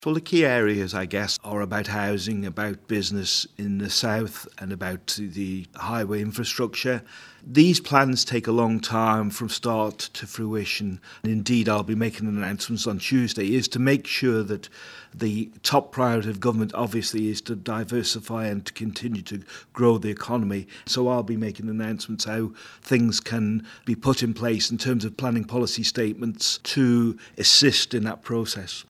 There's been numerous developments in the proposed Southern Area Plan, which the Infrastructure Minister tells 3FM will be to the benefit of everyone.
David Cretney outlined the current state of affairs with regards to the proposals and what he expects will happen over the coming months.